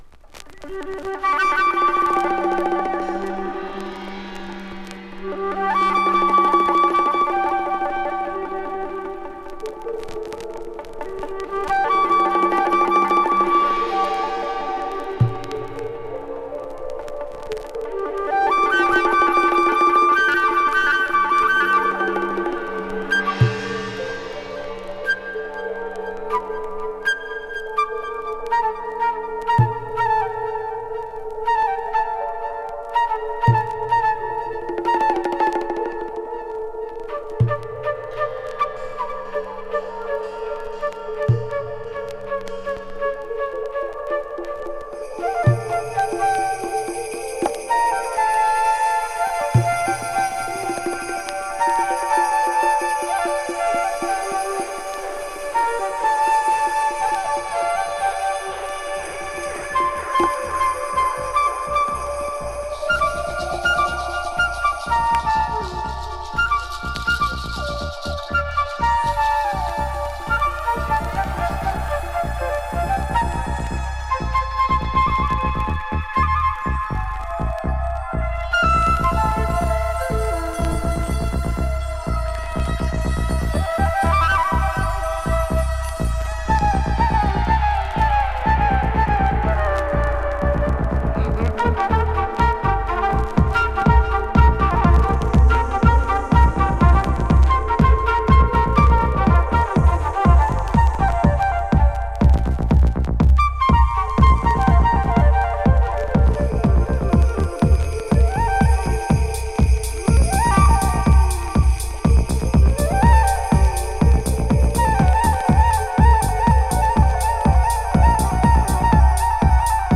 あのベースラインはそのままに、重厚なトライバル・トラックへ変換。